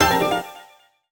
collect_item_06.wav